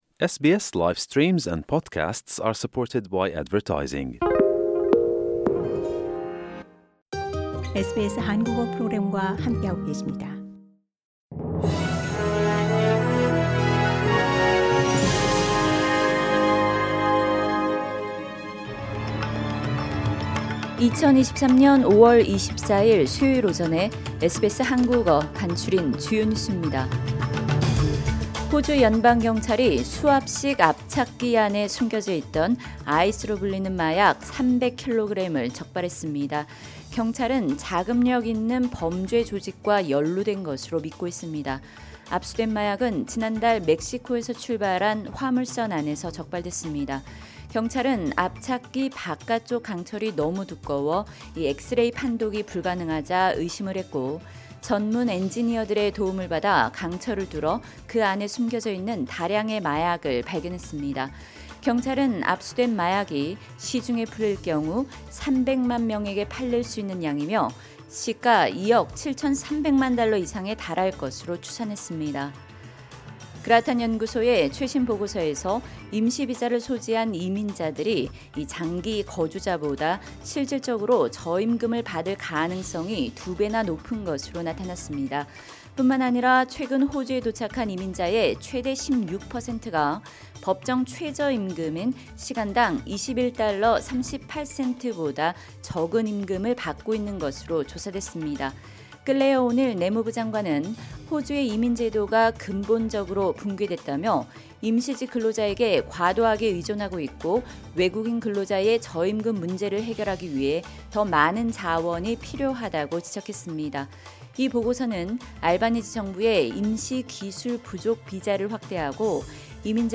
SBS 한국어 아침 뉴스: 2023년 5월 24일 수요일